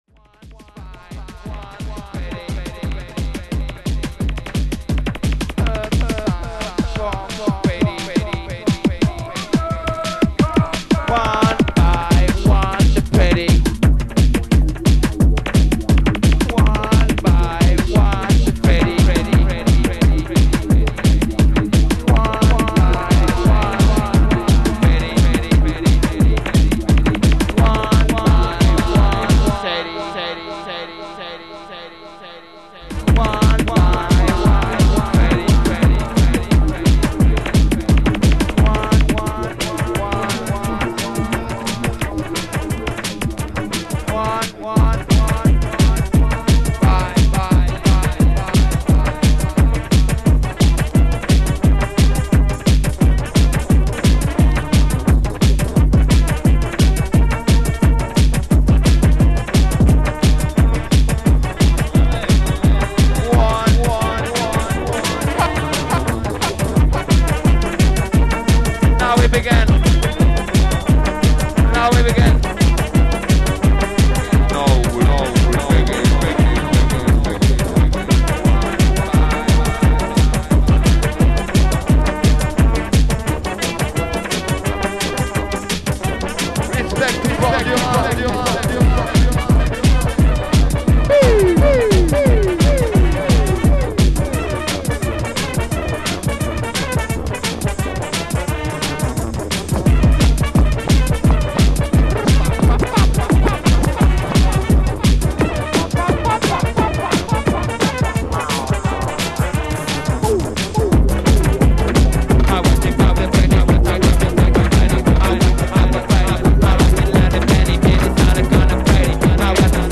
petite salle